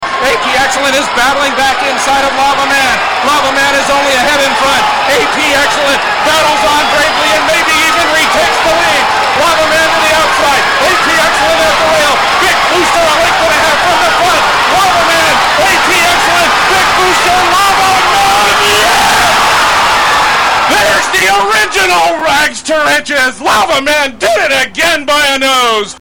Just in case anybody missed the stretch call.